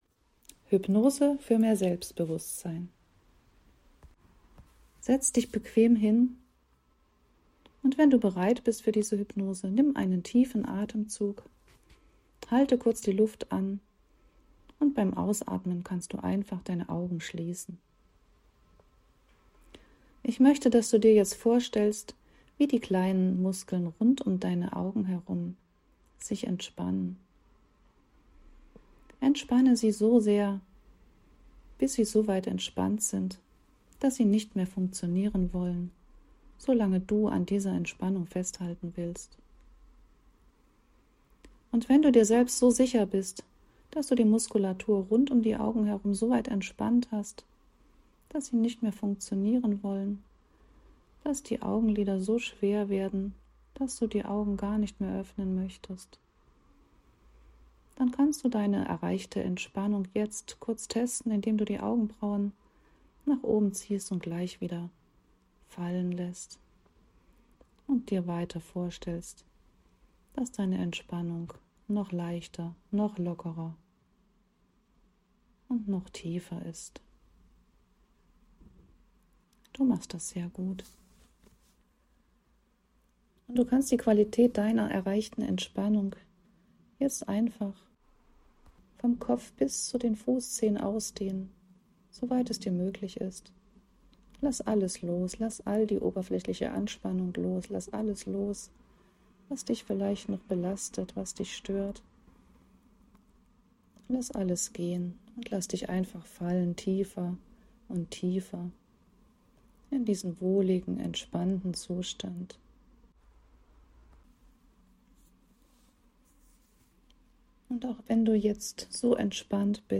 hyonose für mehr selbstbewusstsein.mp3